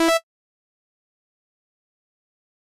フリー効果音：システム12
フリー効果音｜ジャンル：システム、システム系第12号！ピコっ！としてて何にでも使いやすいです！